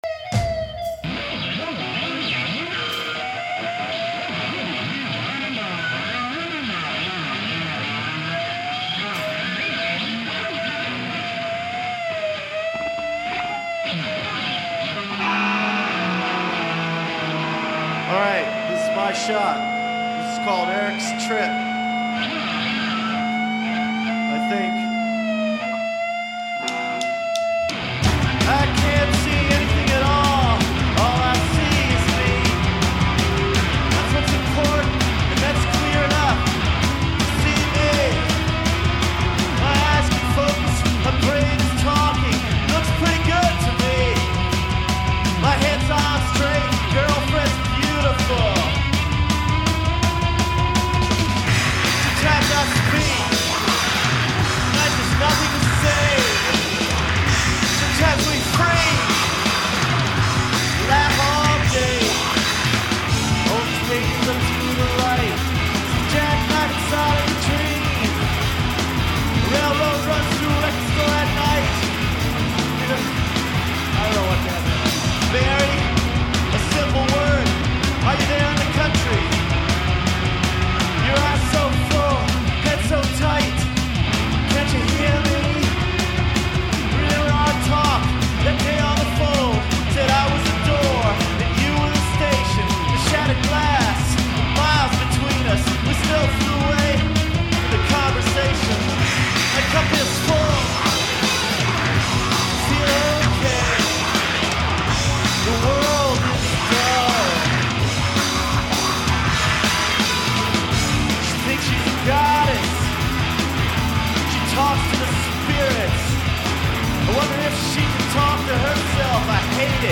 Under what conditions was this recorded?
Live at Irvine, CA 11/03/90